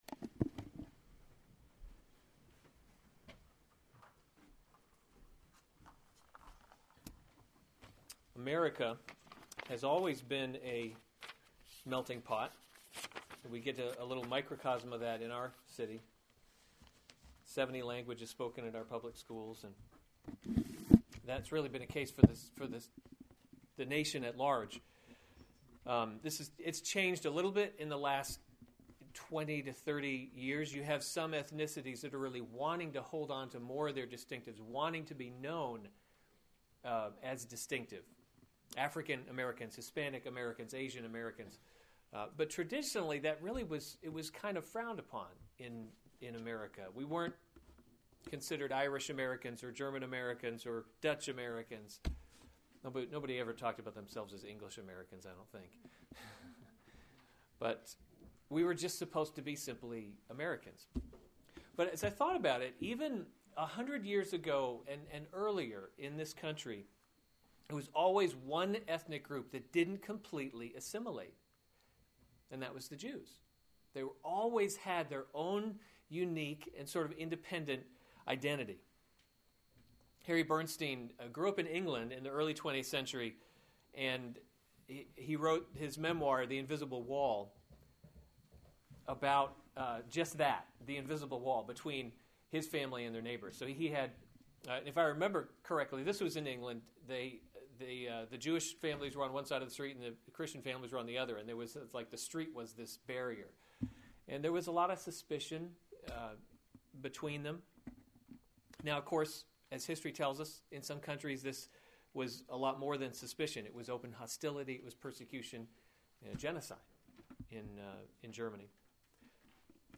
January 10, 2015 Romans – God’s Glory in Salvation series Weekly Sunday Service Save/Download this sermon Romans 11:1-10 Other sermons from Romans The Remnant of Israel 11:1 I ask, then, has […]